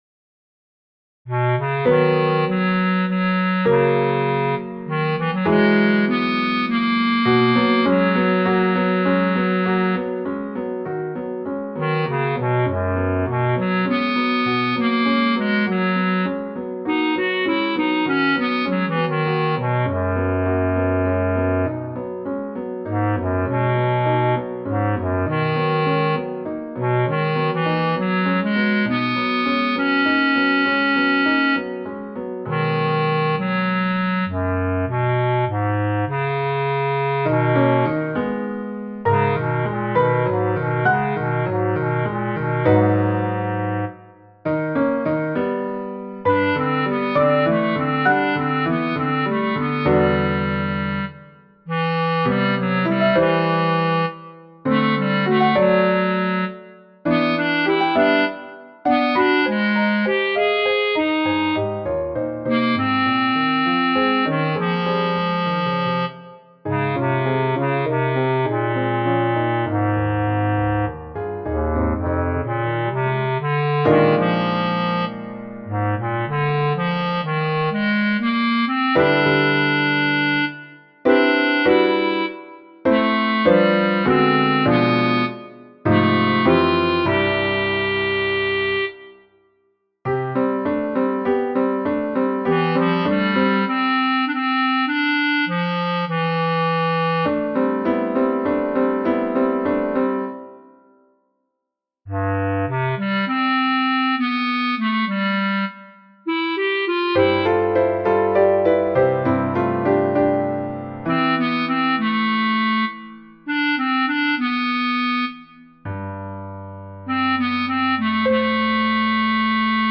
Two players - no more, no less.
Bass Clarinet and Piano MS